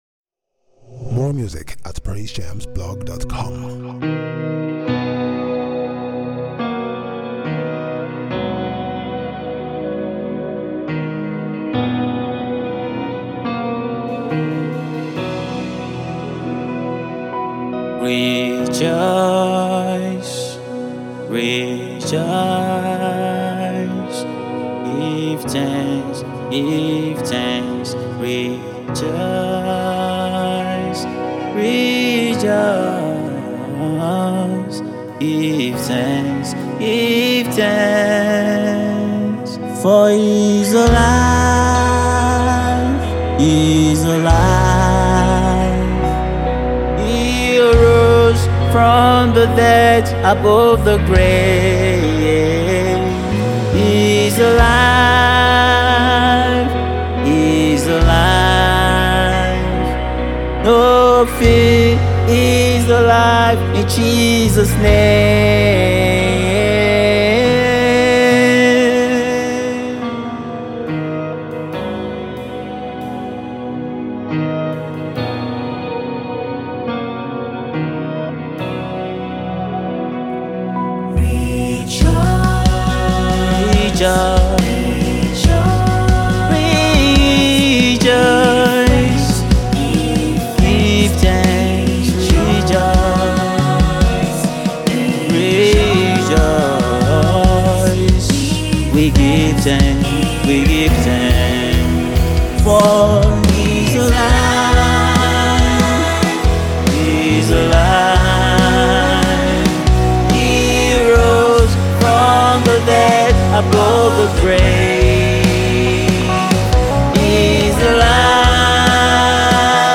Nigerian US-based iconic gospel music artist and songwriter